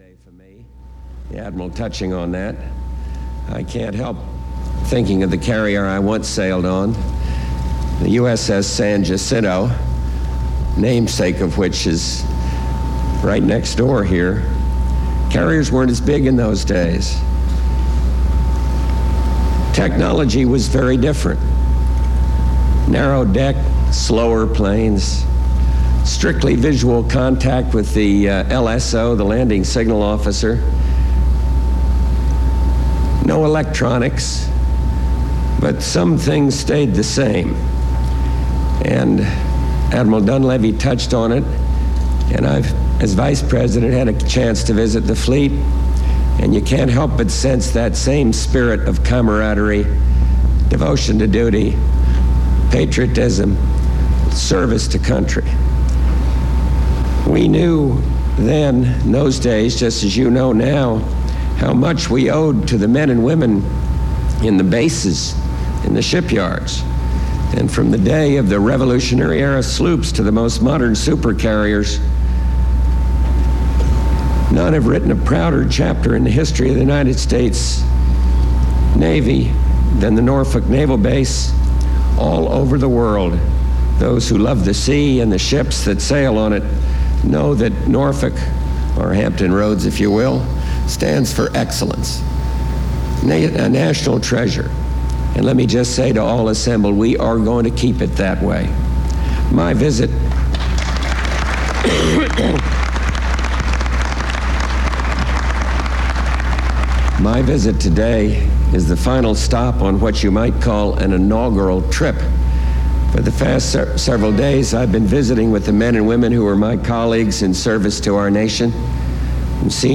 In his first Presidential speech outside of Washington, D.C., George Bush tells the crew of the carrier America in Norfolk, Virginia, that servicemen will get the support of the shipbuilders and the American public.
Navy Material Type Sound recordings Language English Extent 00:12:00 Venue Note Broadcast on CNN, January 31, 1989.